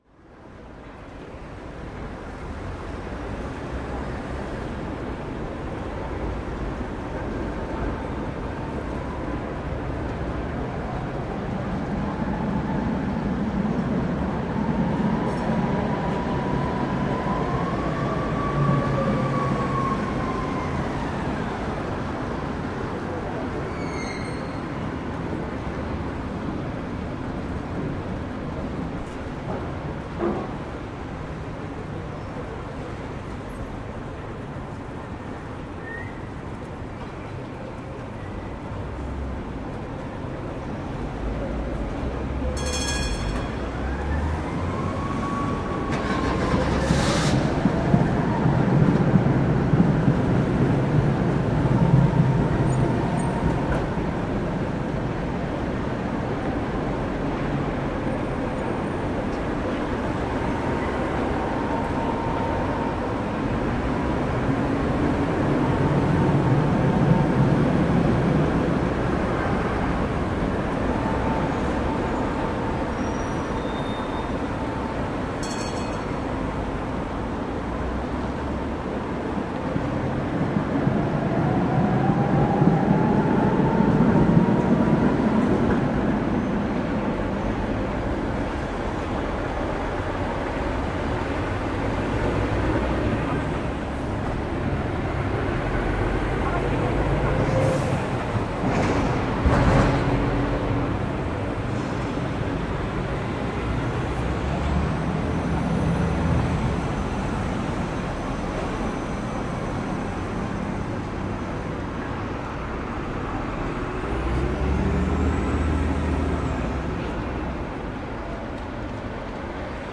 环境 " 汉堡市交通环境
描述：下午6点，汽车在红绿灯，十字路口，四车道公路，过往车辆，公共汽车，摩托车，脚步声，一般嗡嗡声，汽车，城市等候 录音机/麦克风：放大H4n Pro（立体声XY） 此声音受许可协议约束。
标签： 噪音 街道 路口 汉堡 摩托车 摩托车 音景 环境 交通 现场记录 一般噪音 大气
声道立体声